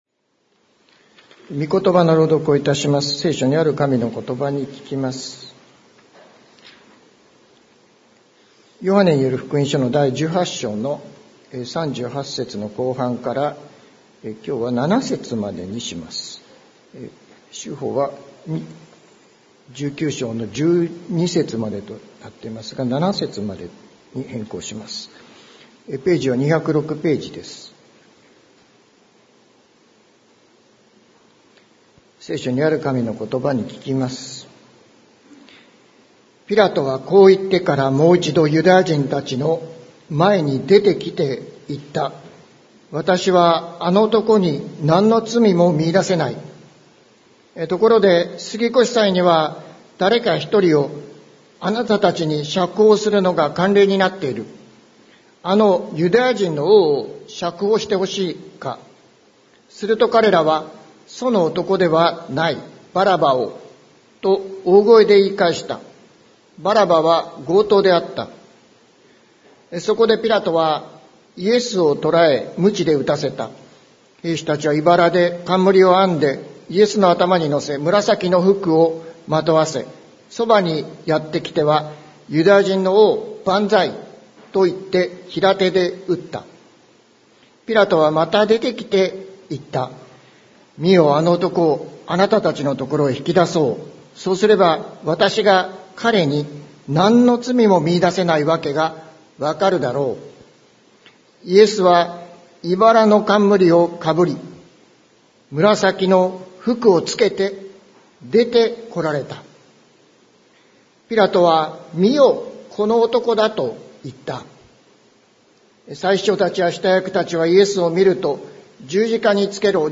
2023年11月26日朝の礼拝「この人を見よ」関キリスト教会
説教アーカイブ。